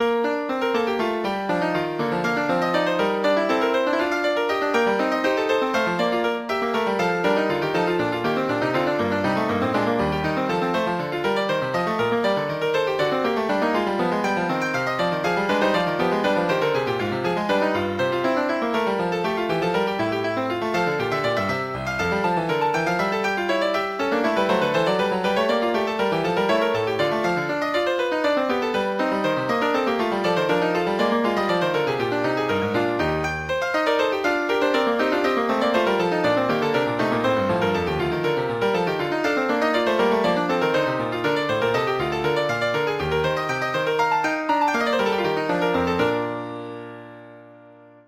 Piano version
Classical Piano